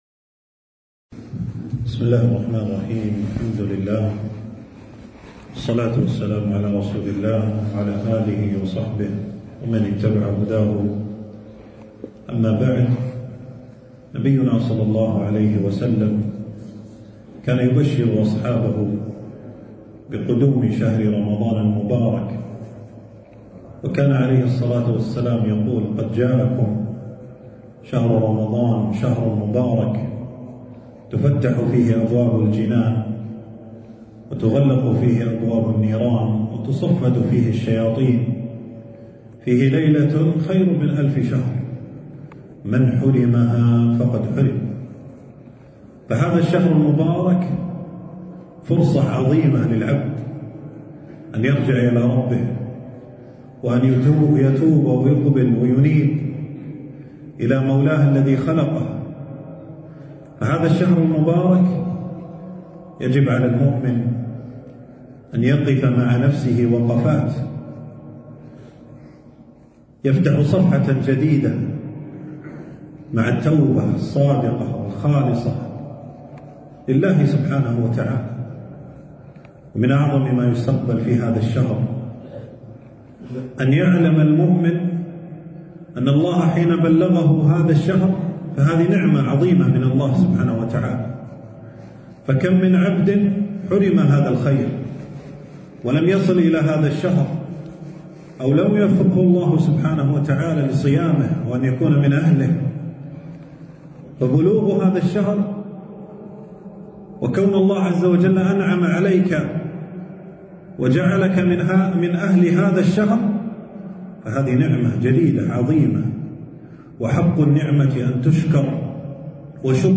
خاطرة - موعظة قبل رمضان